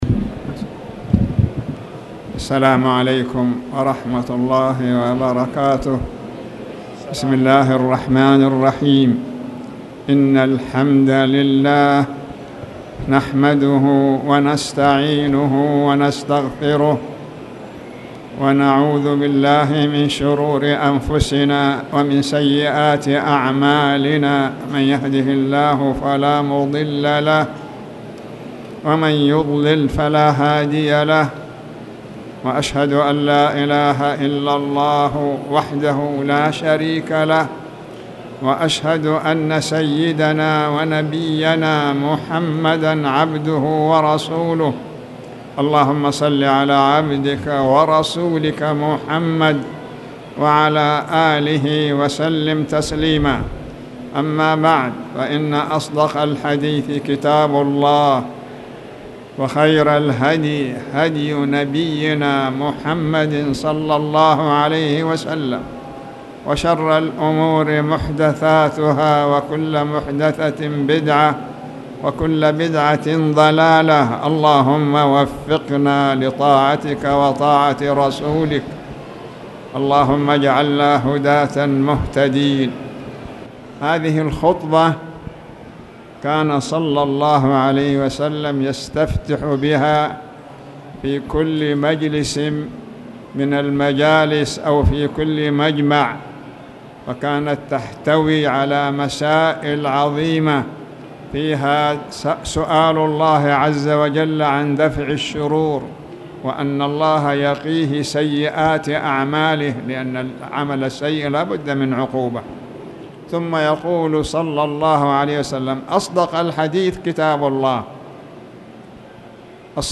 تاريخ النشر ١ صفر ١٤٣٨ هـ المكان: المسجد الحرام الشيخ